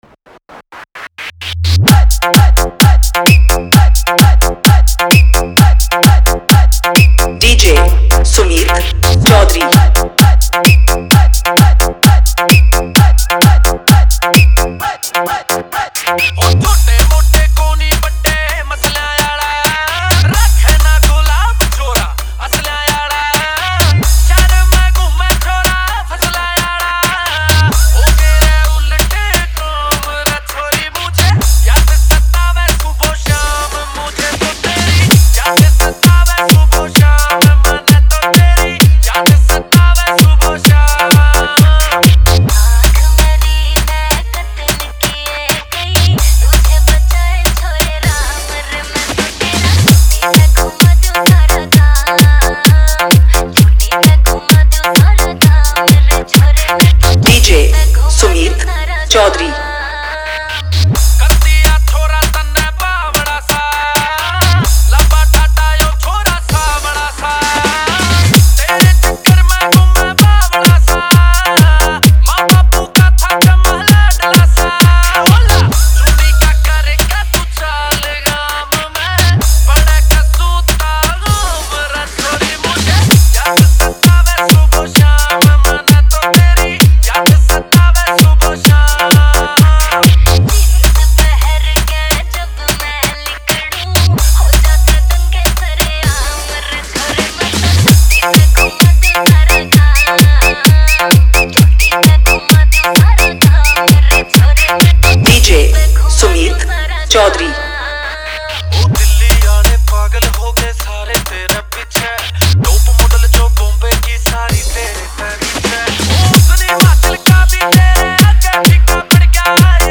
Haryanvi Remix Report This File Play Pause Vol + Vol -